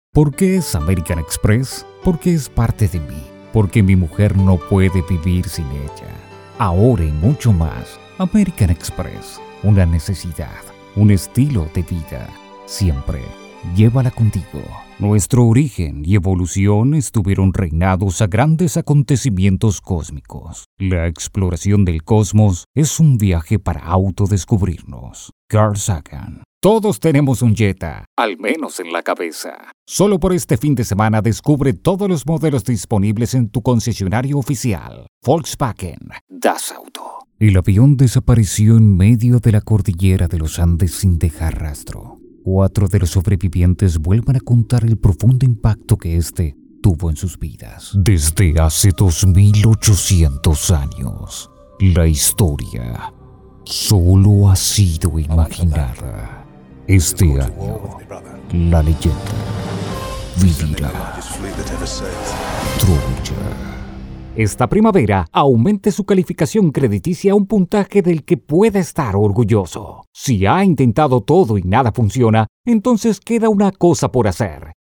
Masculino
Espanhol - América Latina Neutro
Voz Padrão - Grave 01:14